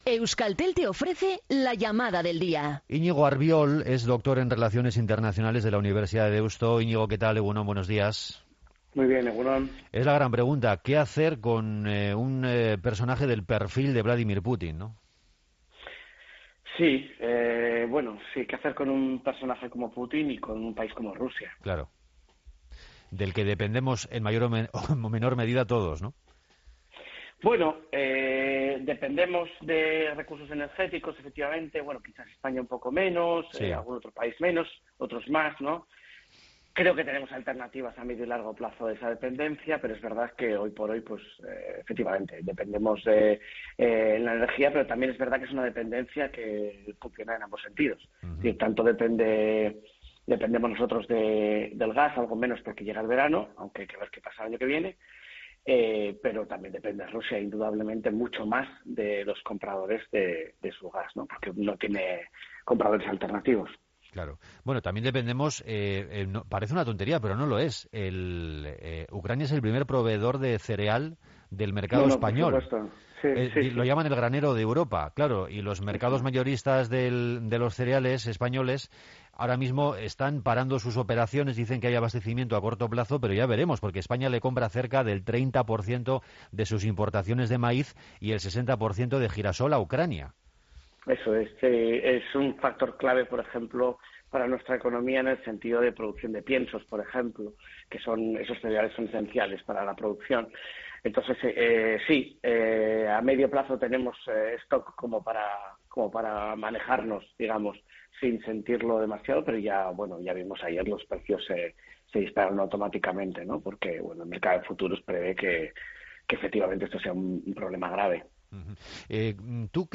Morning show conectado a la calle y omnipresente en la red.
Entrevista completa